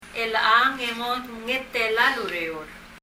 発音
élechang　　　[elɛ ? əŋ]　　　今日